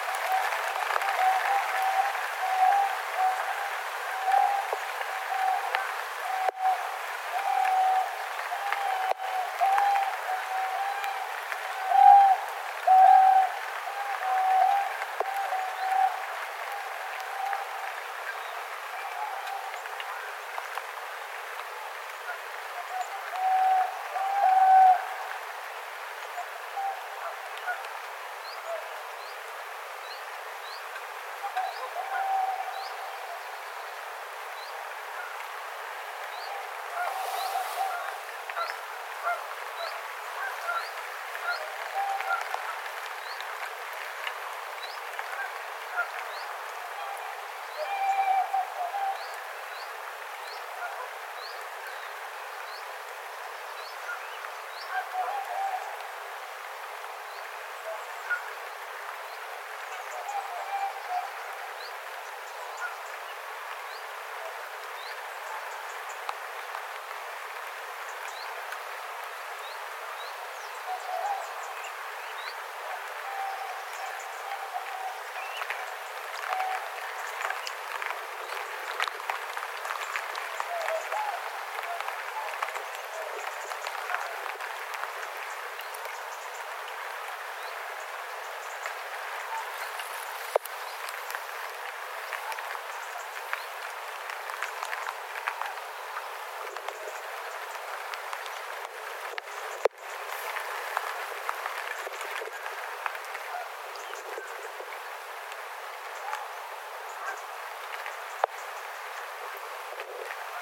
lintutornissa äänitetty
aanitetty_lintutornissa.mp3